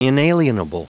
Prononciation du mot inalienable en anglais (fichier audio)
Prononciation du mot : inalienable